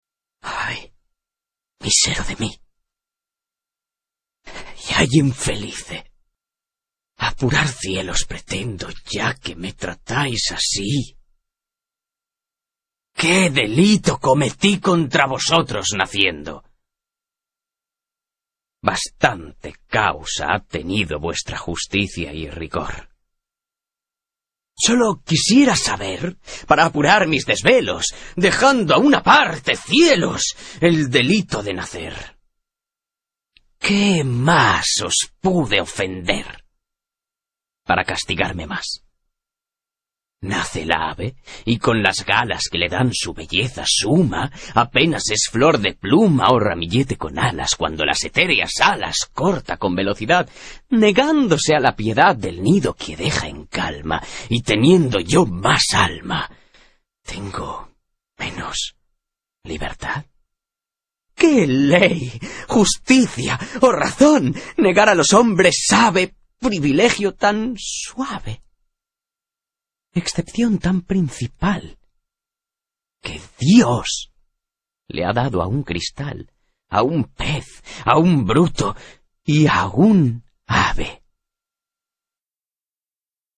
Speaker madrelingua spagnolo dalla voce calda e versatile.
Sprechprobe: Sonstiges (Muttersprache):
Native Spanish voice-artist with a warm and versatile voice.